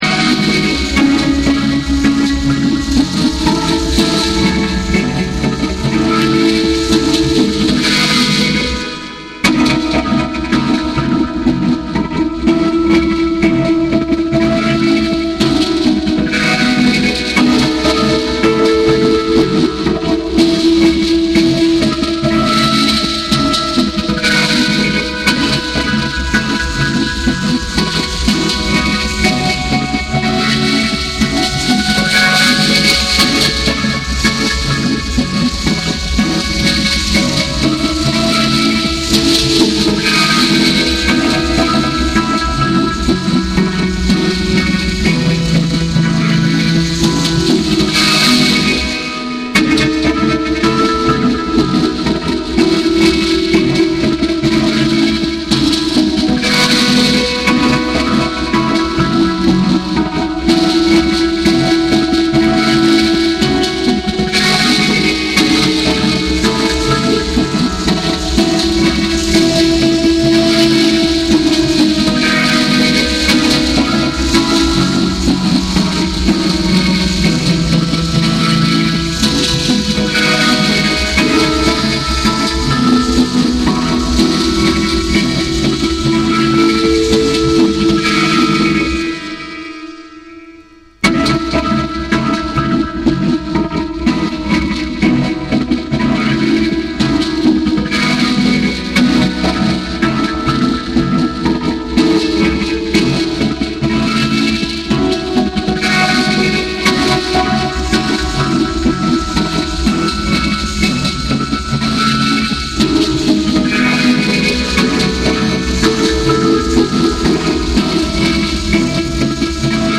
BREAKBEATS
カリビアン・テイストなトロピカルナンバーから、もちろんレゲエ〜ダブまで楽しめる隠れた（？）好作！